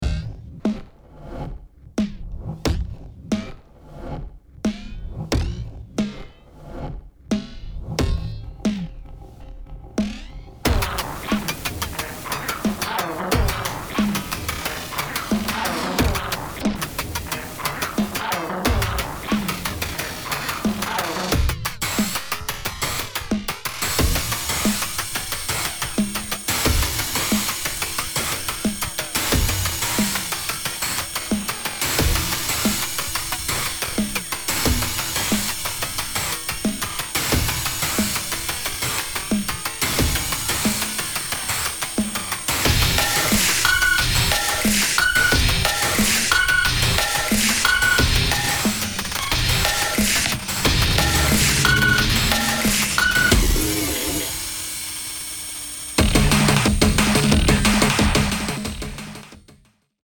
今回もガムラン的なパーカッションアレンジに血湧き肉躍る熱帯生まれの新型インダストリアルを展開。